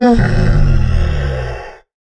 Minecraft Version Minecraft Version snapshot Latest Release | Latest Snapshot snapshot / assets / minecraft / sounds / mob / camel / sit2.ogg Compare With Compare With Latest Release | Latest Snapshot